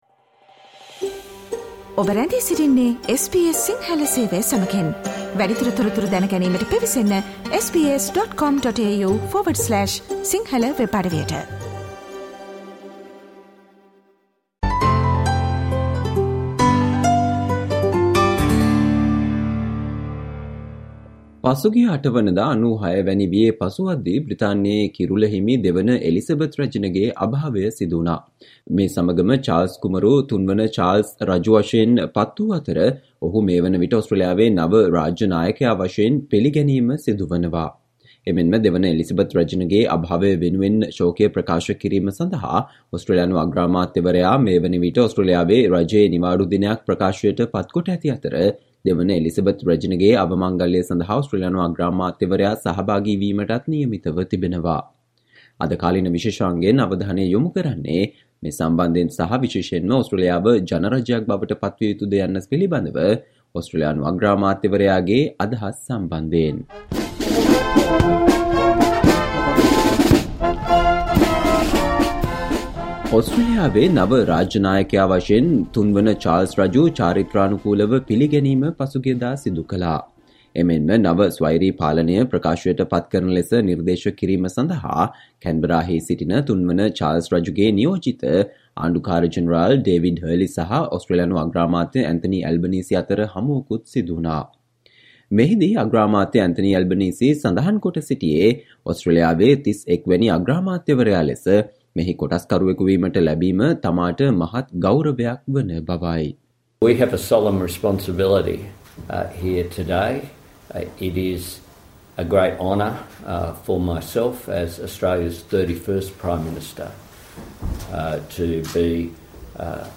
Today - 12 September, SBS Sinhala Radio current Affair Feature on Australia proclaims Charles III as its new king